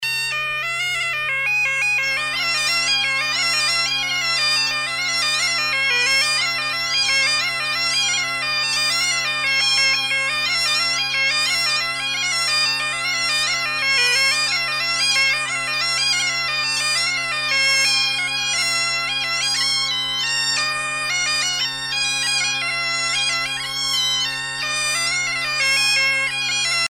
danse : mazurka
Pièce musicale éditée